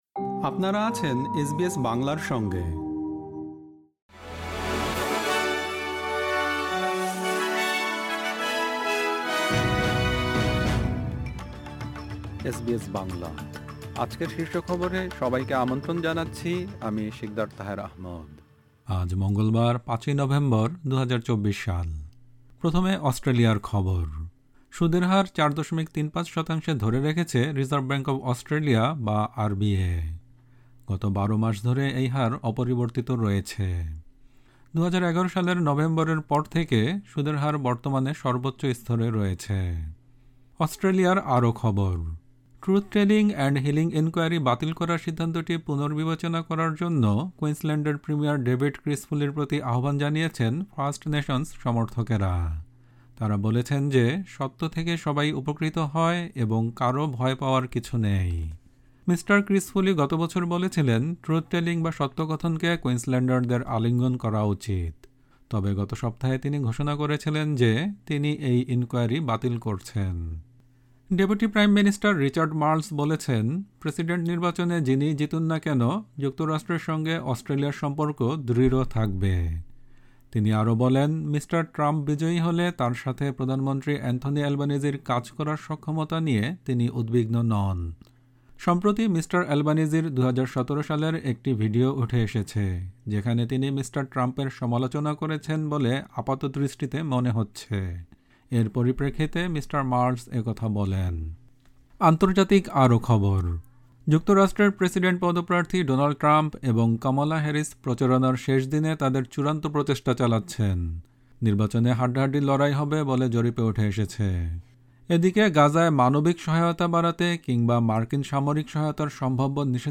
এসবিএস বাংলা শীর্ষ খবর: ৫ নভেম্বর, ২০২৪